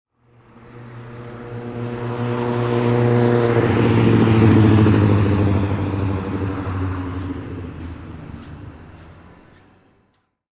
Motorcycle Passing Sound Effect
Authentic close-up recording of a large motorcycle passing at medium speed on a city street.
Motorcycle-passing-sound-effect.mp3